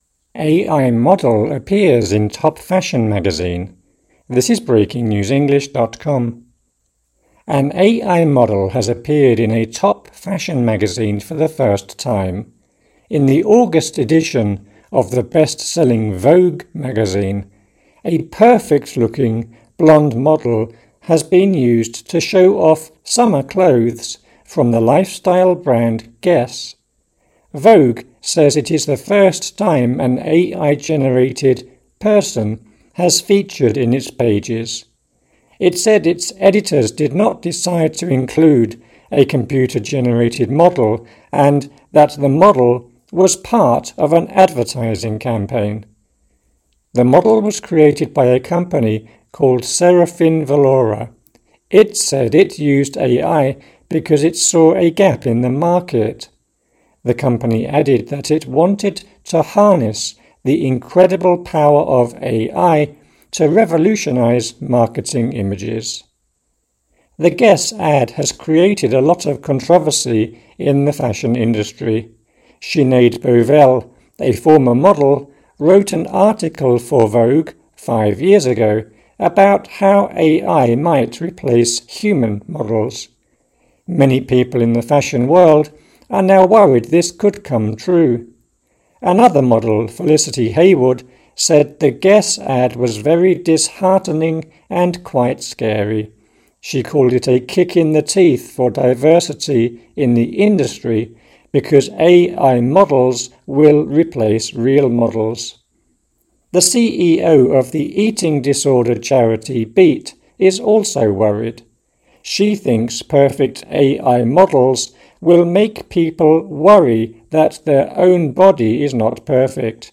AUDIO (Slow)